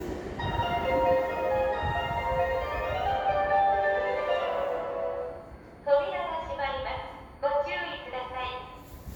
・2000系車載メロディ（2023/3頃〜）
相鉄直通に際するイメージチェンジからかメロディが変更され、2回繰り返しという事も無くなったことから扱いやすくなりました。2000系は音量も大きいので録音しやすいかと。